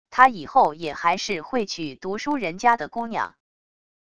他以后也还是会娶读书人家的姑娘wav音频生成系统WAV Audio Player